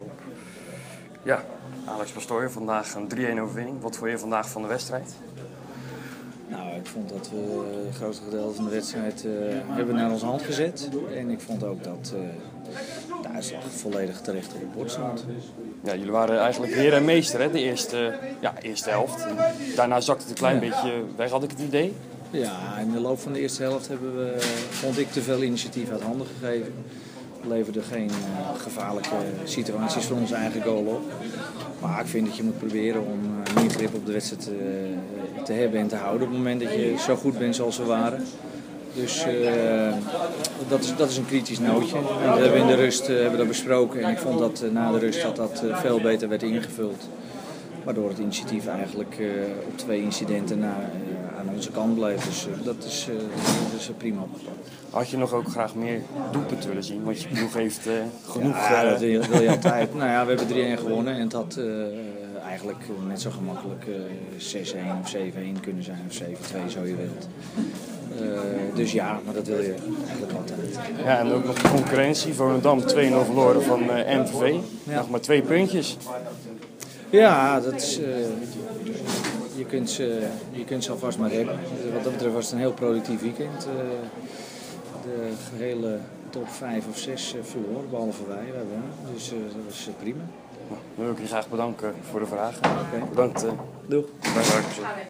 Interview-Alex-Pastoor.m4a